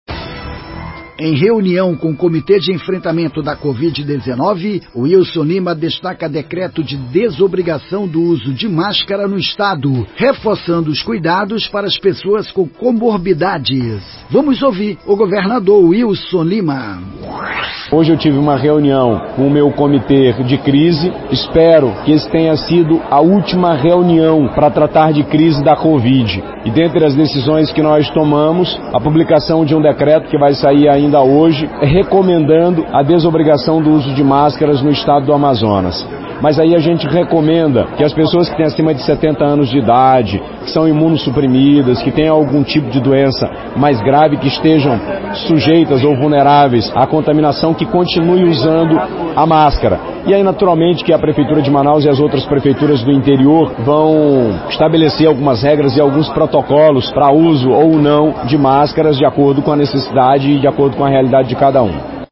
WILSON LIMA – GOVERNADOR DO AMAZONAS
TATYANA AMORIM – PRESIDENTE DA FVS-RCP